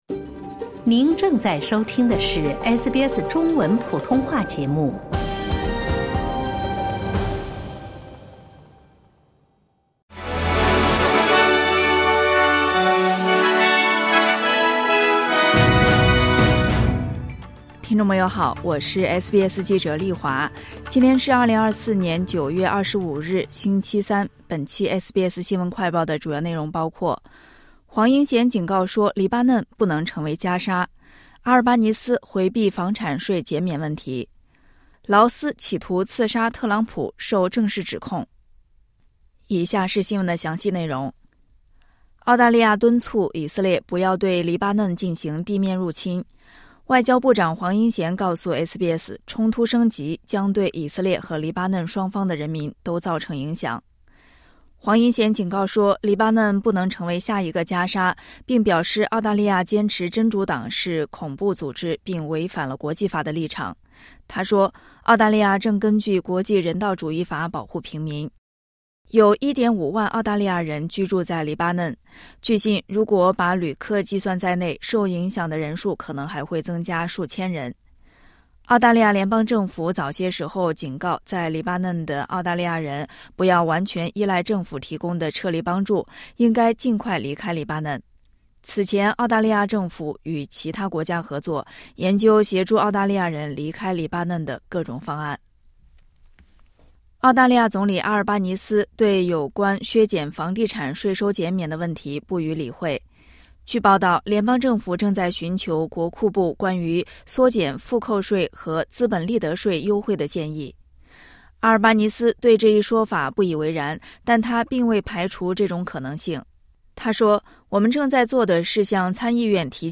【SBS新闻快报】黄英贤警告说黎巴嫩不能成为加沙